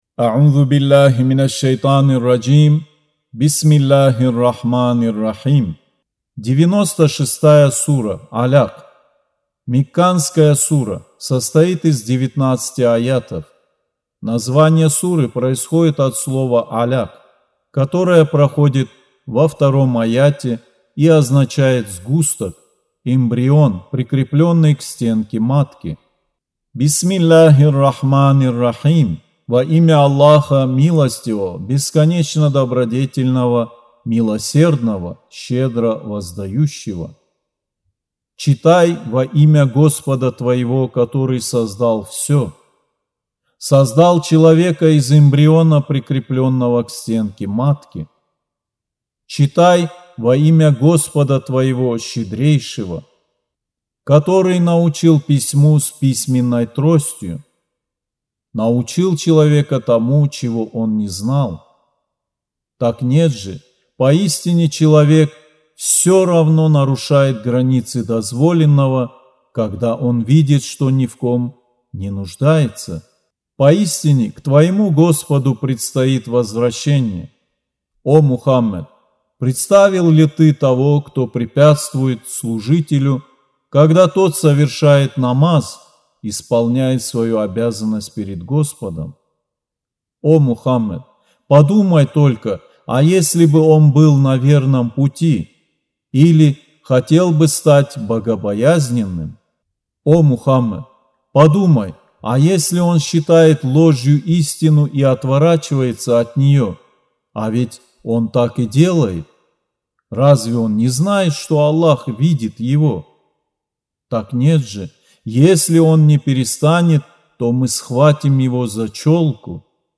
Аудио Коран 96.